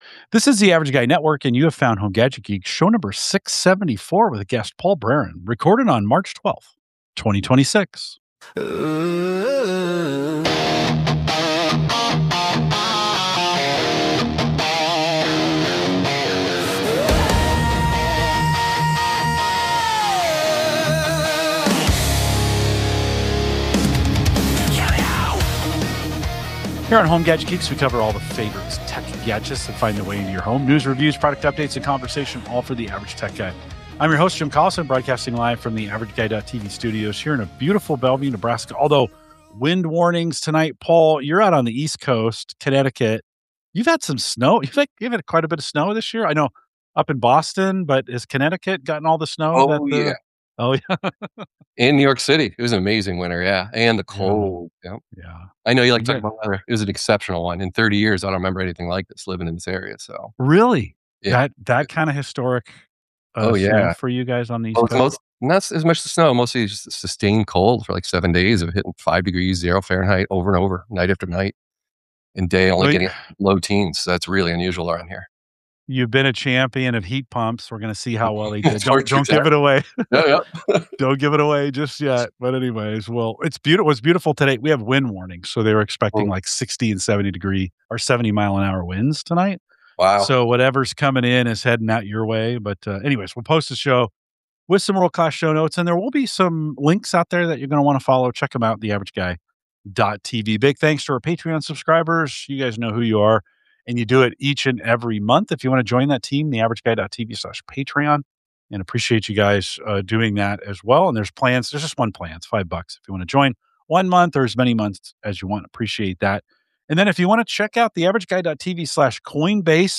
They explore the real-world costs of improving home energy efficiency, the role of smart electrical panels and lithium-ion battery backups, and how modern monitoring tools help homeowners better understand their energy usage. The conversation also covers robotics, network upgrades, and the growing role of AI in everyday technology troubleshooting.